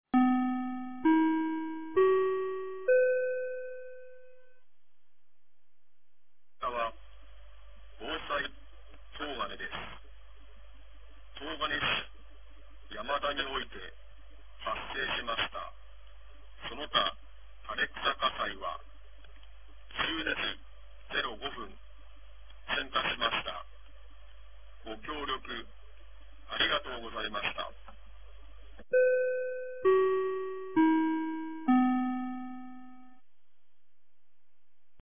2023年02月06日 15時55分に、東金市より防災行政無線の放送を行いました。
放送音声